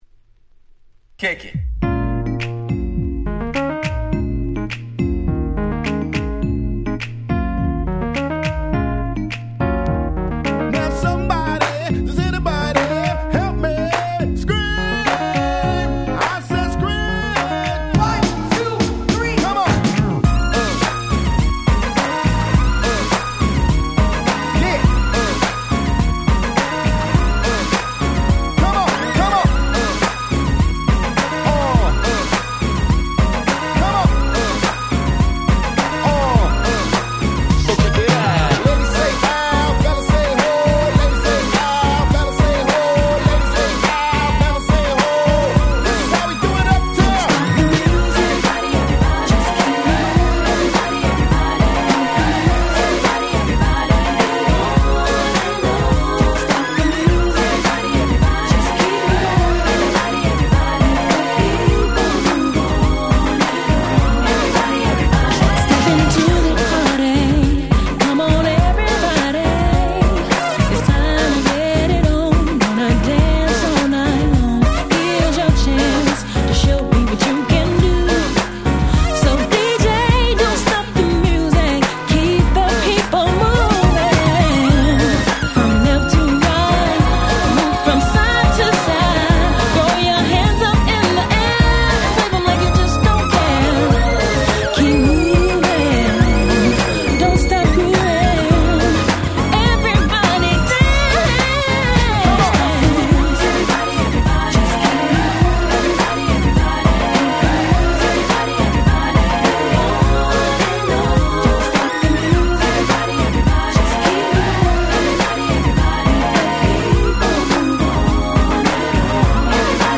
90's R&B デリシャスミックス 勝手にリミックス ミックス物